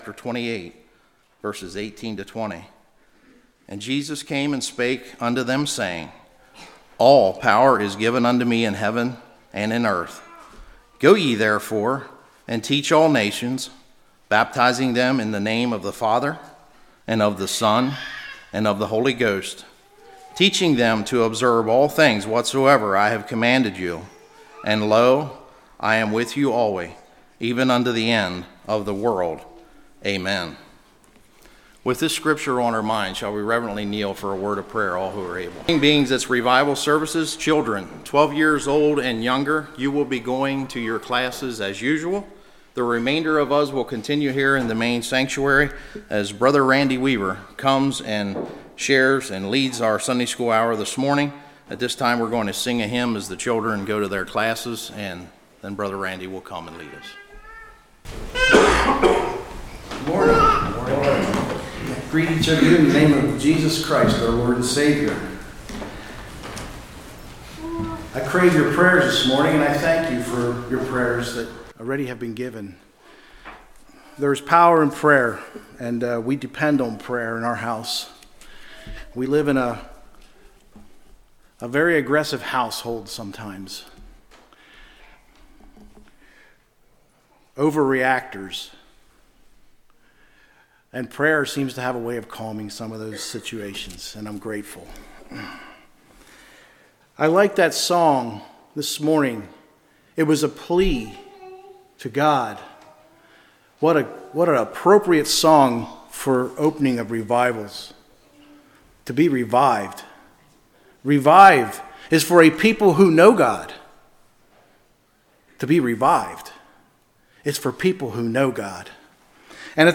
Series: Spring Revival 2025
Service Type: Sunday School